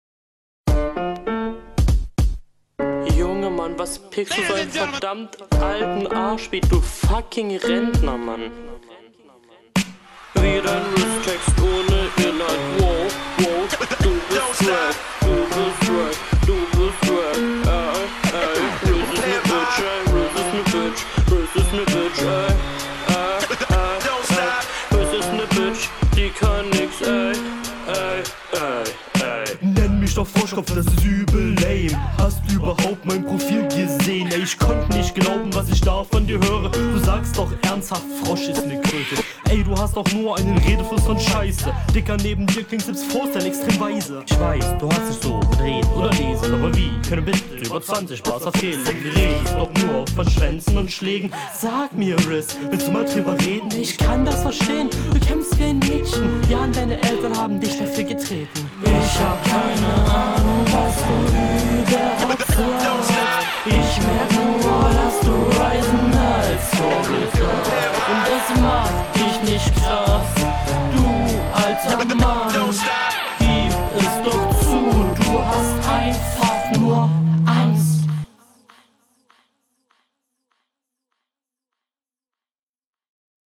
Flow: Etwas schwächer, als dein Gegner, aber nicht so stark wie in den Runden davor.